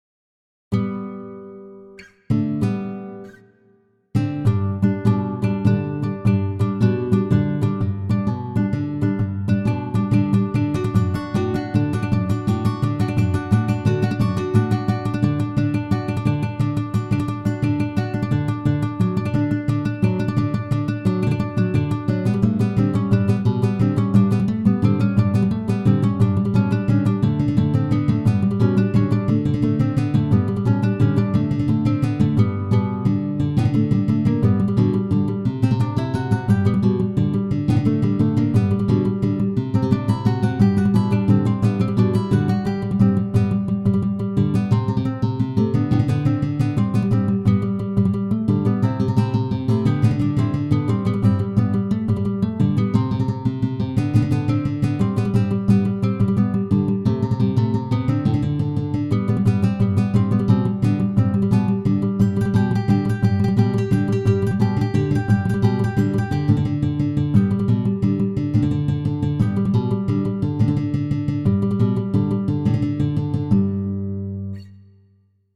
Arrangements for solo guitar of the